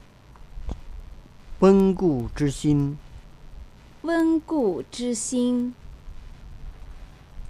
1、温故知新 発音 mp3 发 音:wēn gù zhī xīn 释 义:温：温习。